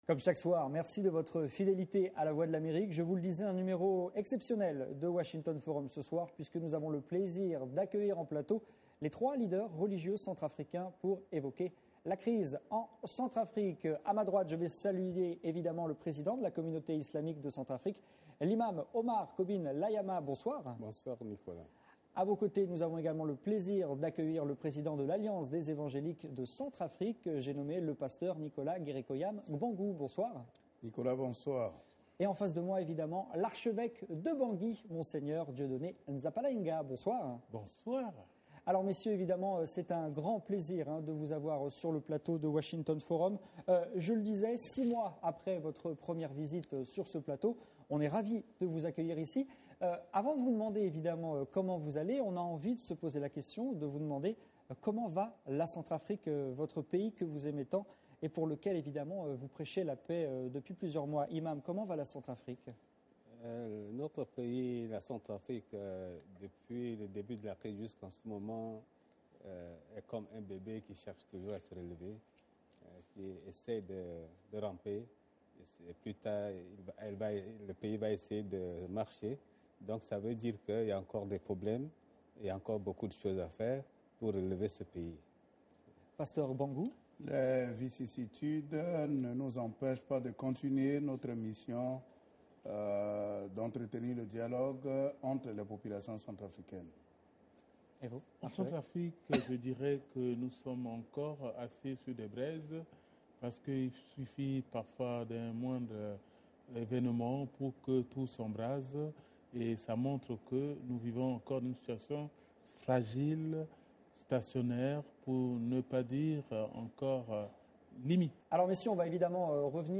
Invités sur la VOA, Nicolas Guérékoyame Gbangou, Oumar Kobine Layama et Dieudonné Nzapalainga se sont exprimés sur la crise centrafricaine depuis Washington.
Emission intégrale avec les trois leaders religieux dans Washington Forum